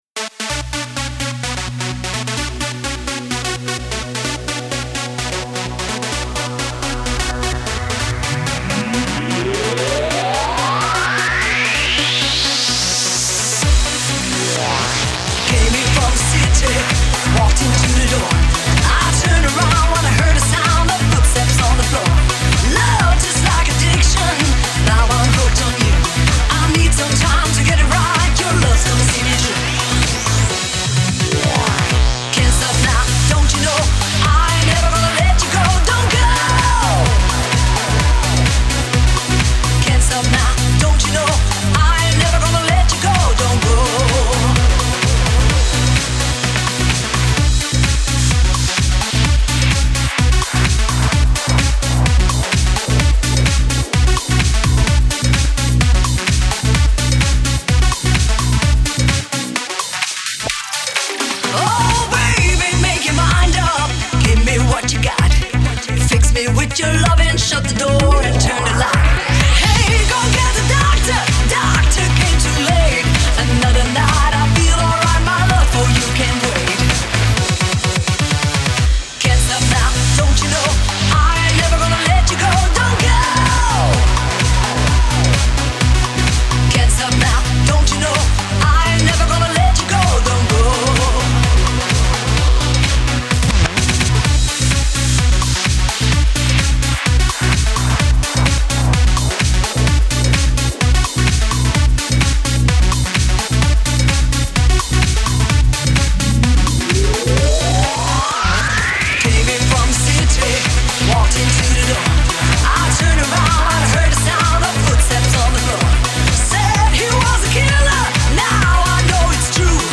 Showband